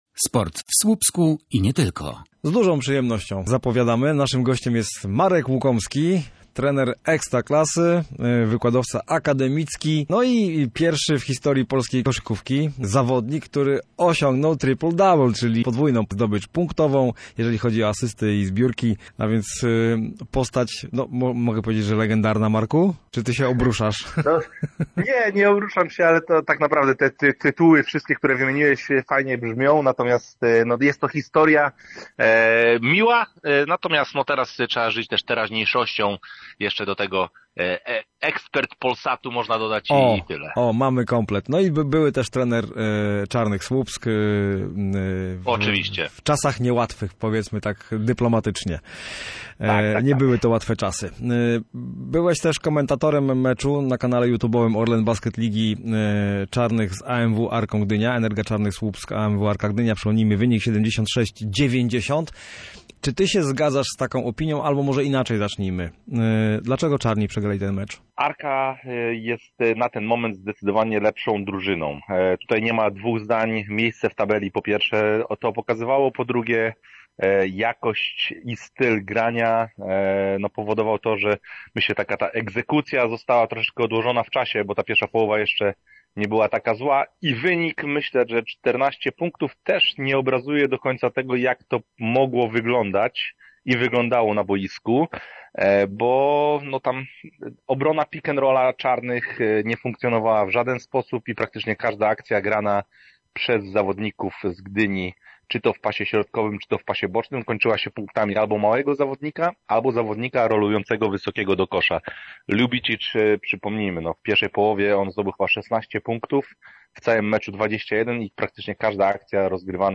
Zmiany w Czarnych nadejdą, jeśli są na to pieniądze w budżecie - wskazał w Studiu Słupsk trener i komentator sportowy